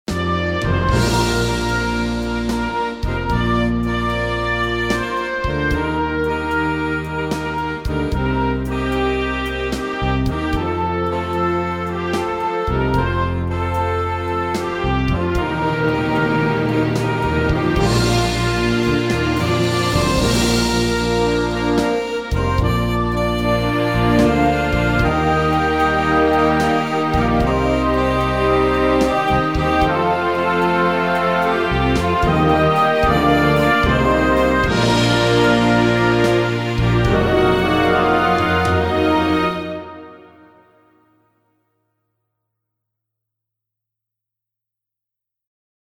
Refrão 1